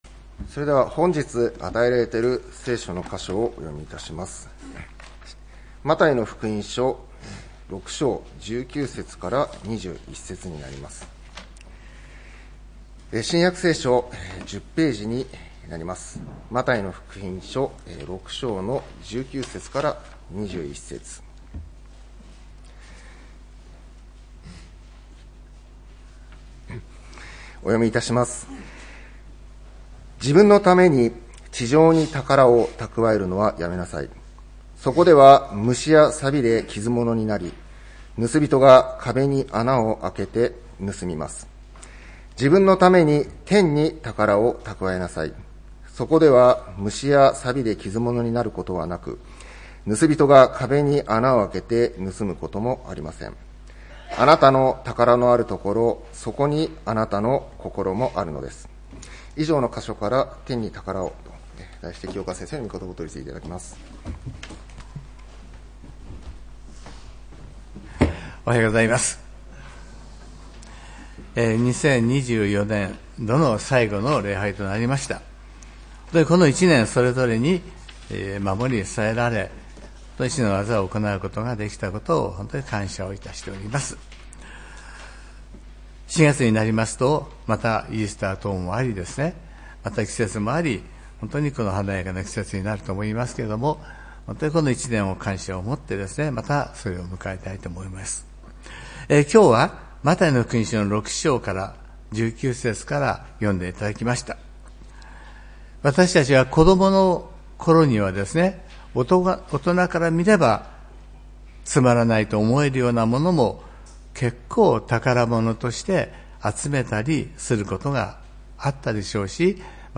礼拝メッセージ「天に宝を」(３月30日）